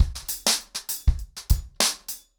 BellAir-A-100BPM-20.wav